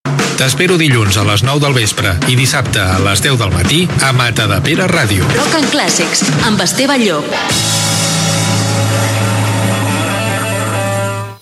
Promoció del programaa
FM